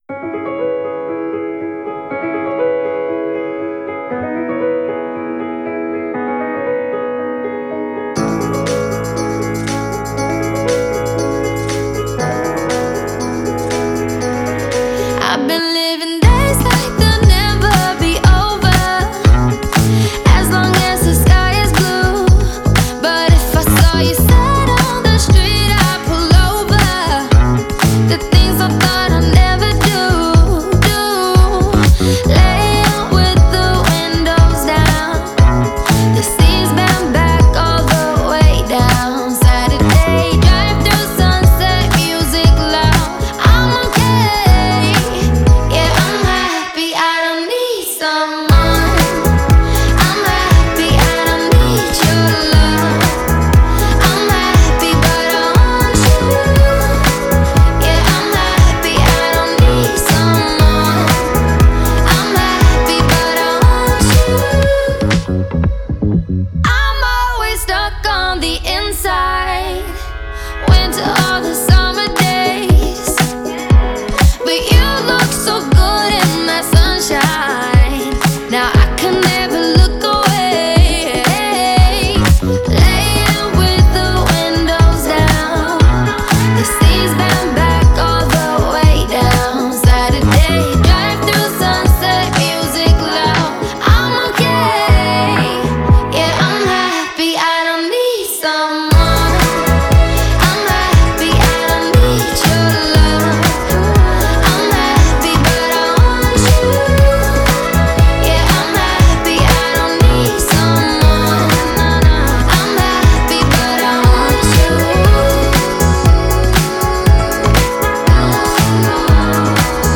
поп-баллада
шведской певицы